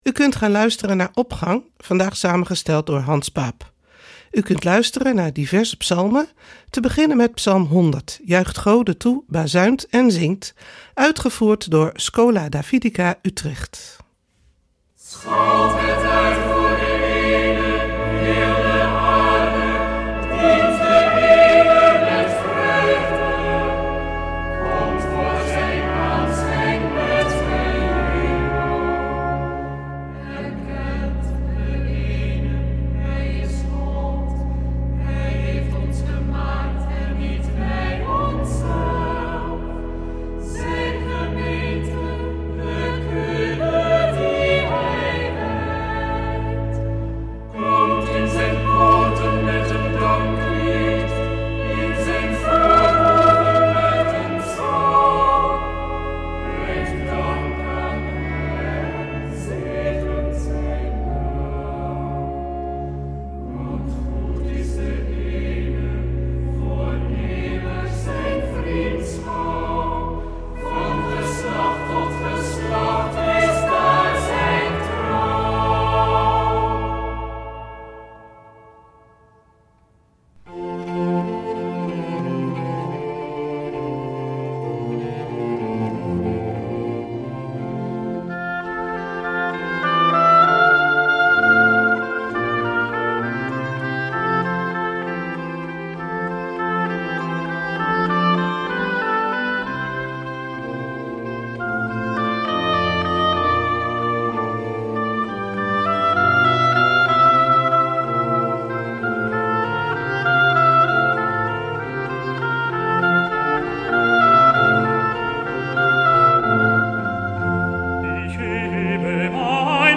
Opening van deze zondag met muziek, rechtstreeks vanuit onze studio.
Uitgevoerd door Schola Davidica Utrecht;